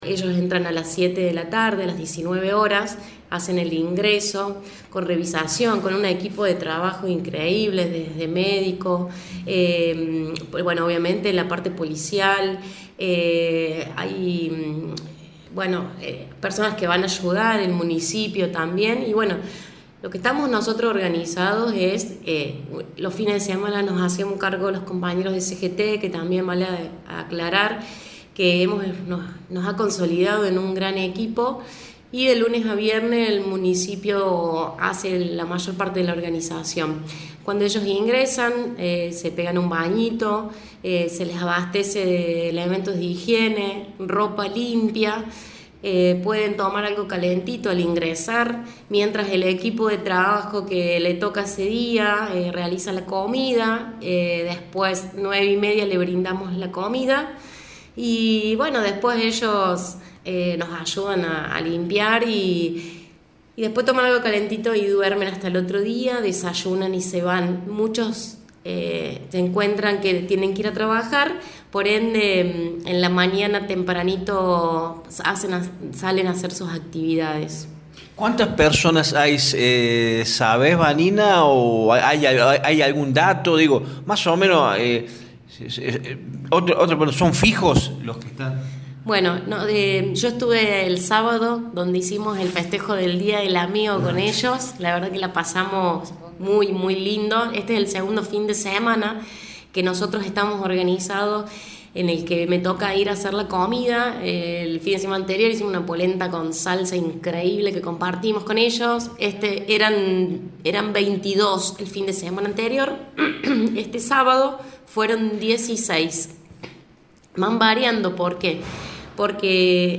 en diálogo con Radio Show explicó